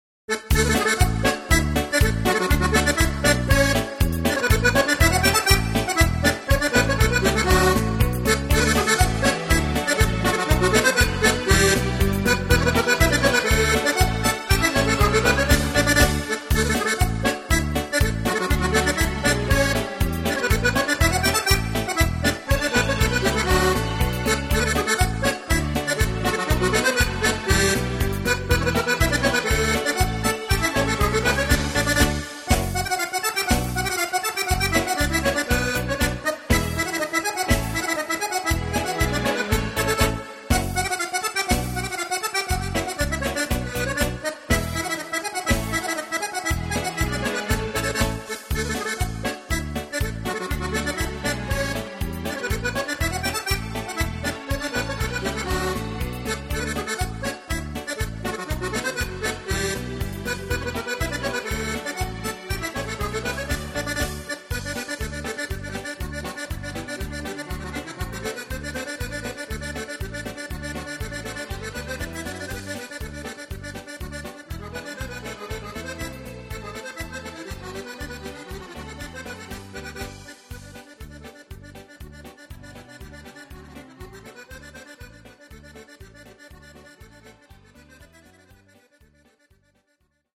Polka